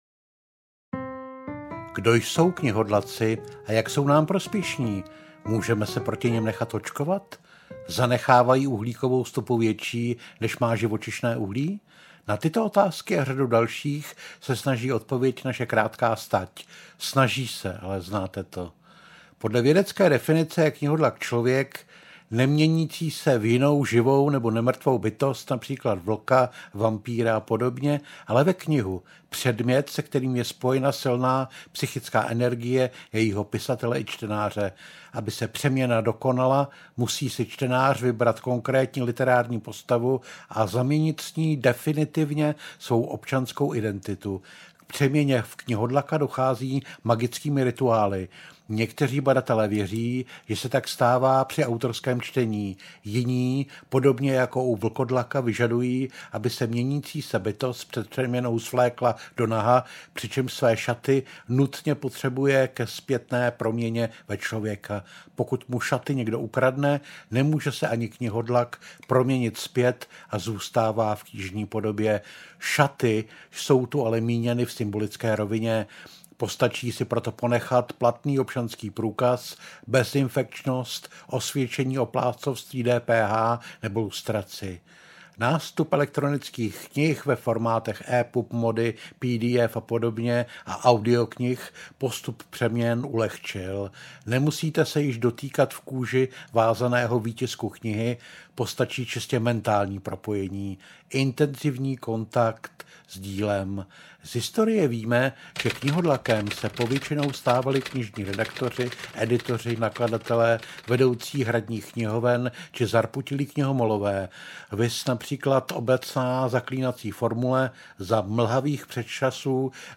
Knihodlak audiokniha
Ukázka z knihy